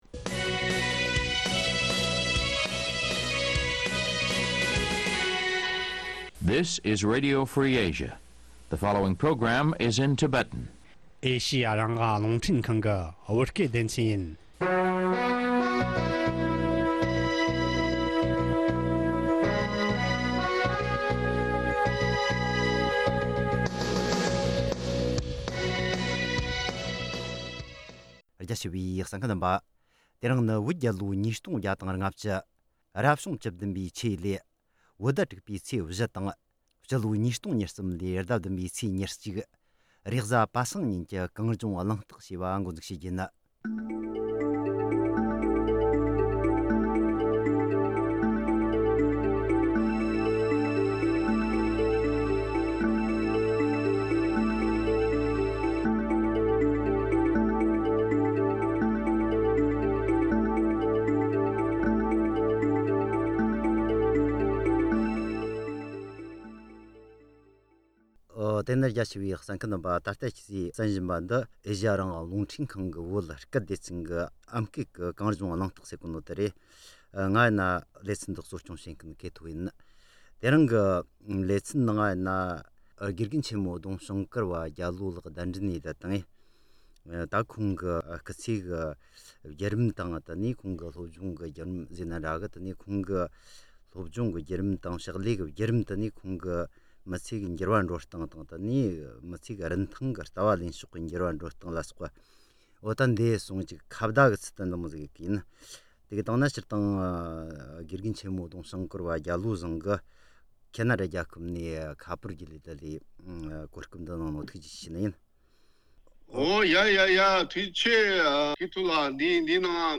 ཁོང་དང་མཉམ་དུ་གླེང་མོལ་ཞུས་པ་ཞིག་ལ་གསན་རོགས་གནོངས།